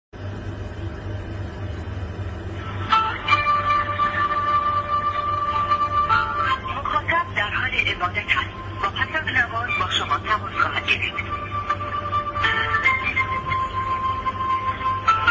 آهنگ پیشواز عبادت، نماز و مذهبی
ebadat-mokhatab.mp3